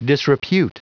Prononciation du mot disrepute en anglais (fichier audio)
Prononciation du mot : disrepute